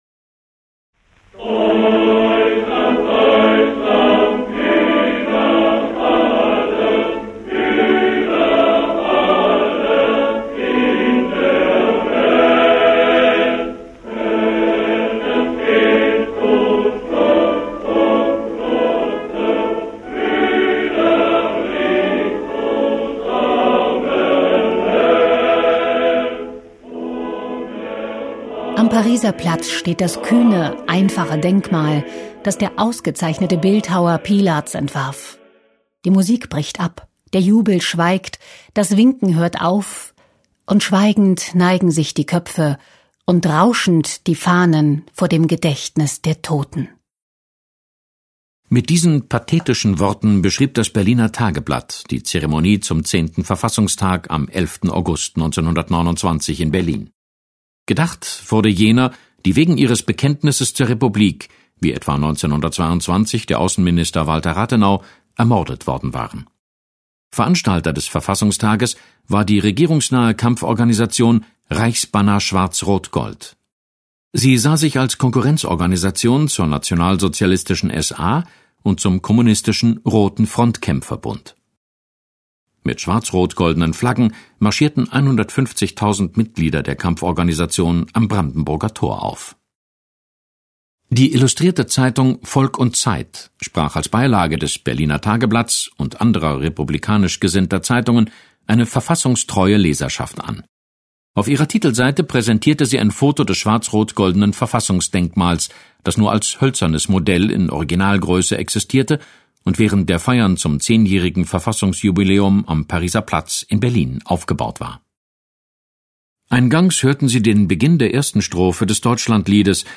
Die Verfassungsfeier in Berlin am Ehrenmal der Republik (1929) (.mp3-Datei)